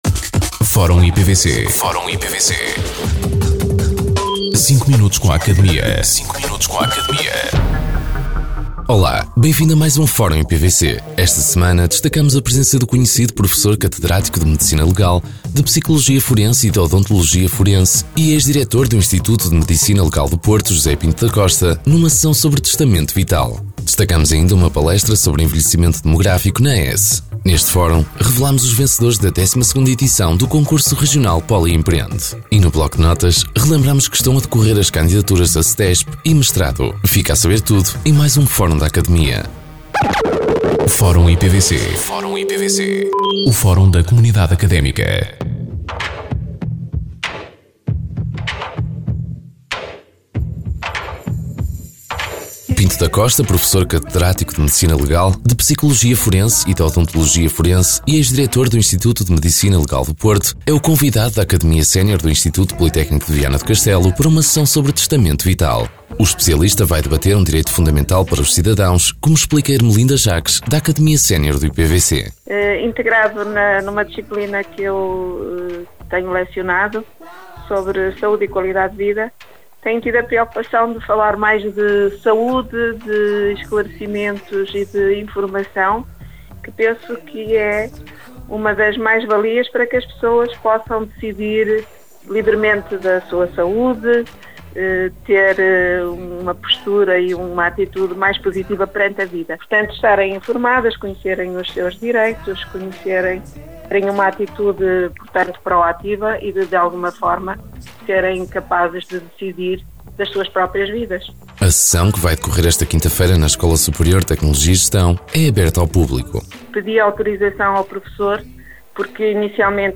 O Instituto Politécnico de Viana do Castelo [IPVC] tem mais um espaço radiofónico a ser transmitido, desta feita, na Rádio Caminha [RJC FM], em 106.2.